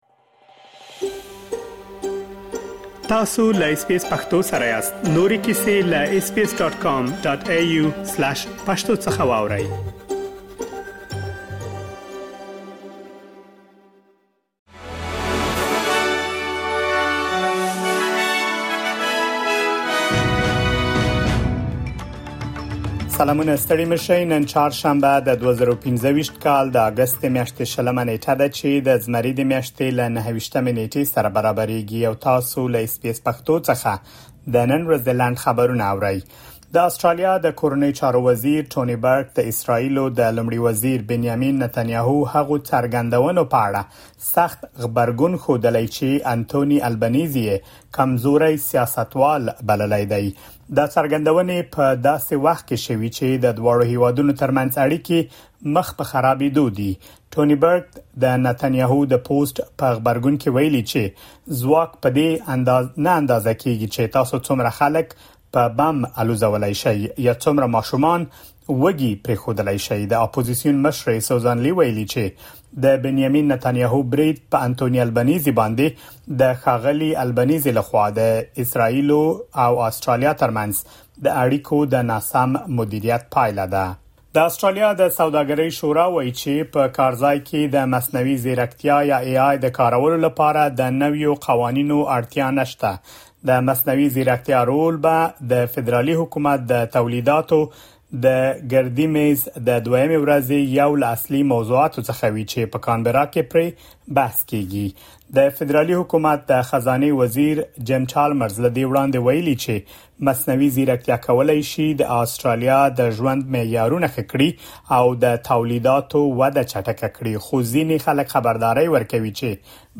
د اس بي اس پښتو د نن ورځې لنډ خبرونه |۲۰ اګسټ ۲۰۲۵
د اس بي اس پښتو د نن ورځې لنډ خبرونه دلته واورئ.